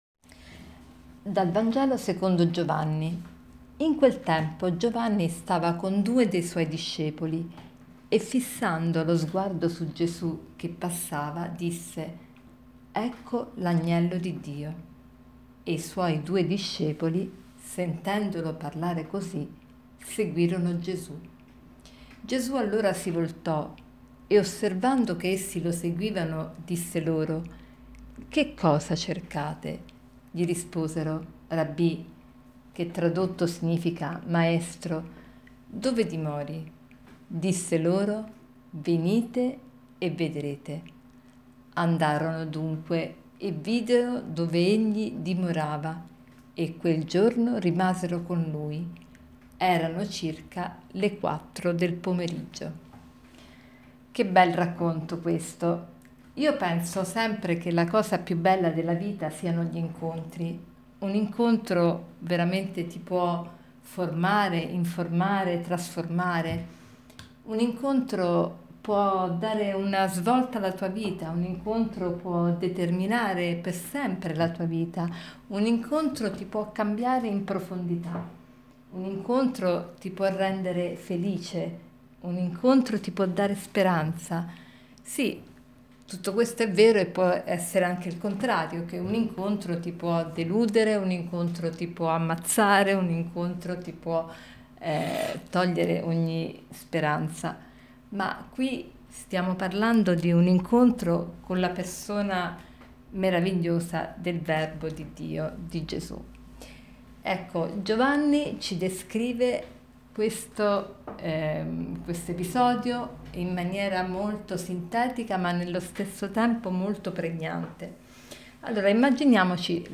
Commento al vangelo (Gv 1, 29-34) di mercoledì 3 gennaio 2018